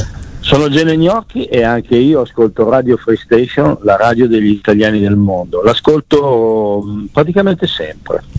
musica / PODCAST RADIOFREESTATION / A...PODCAST INTERVISTE / Ospiti 2017 / GENE GNOCCHI /